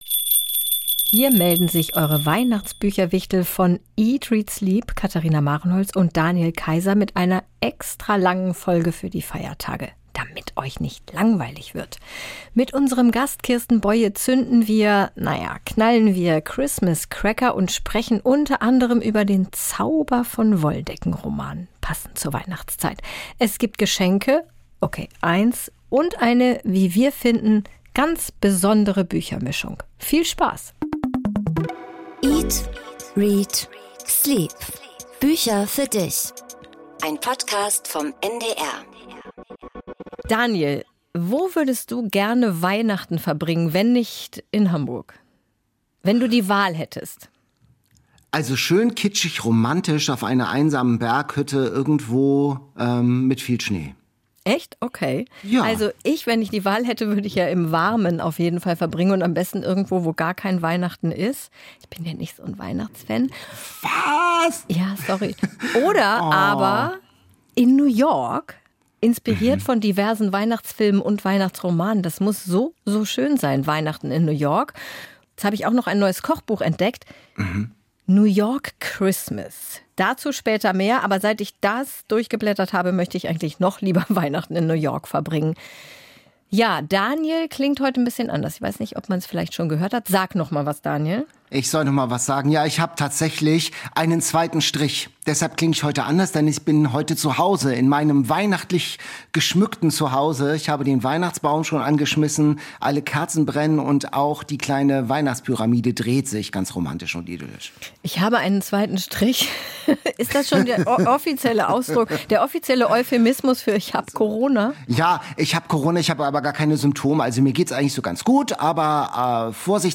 00:35:05 Interview mit Kirsten Boye